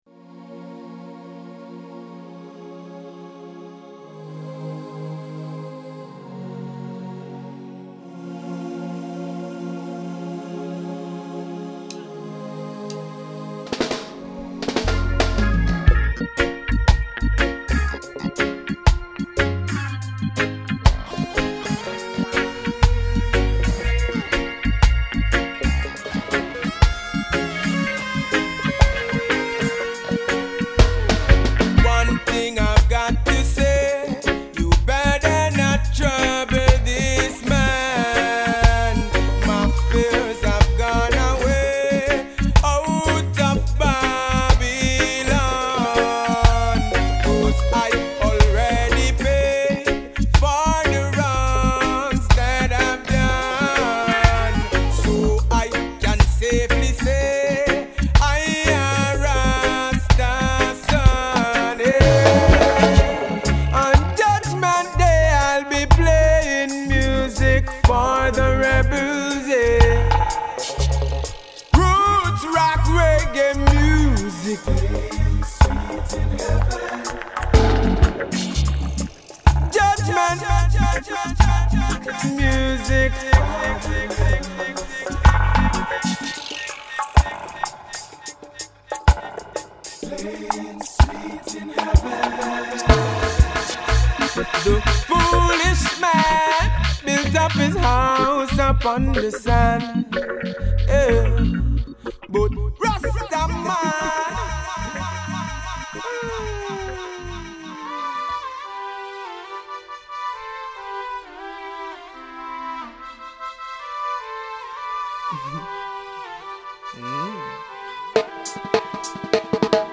an authentic roots rock reggae band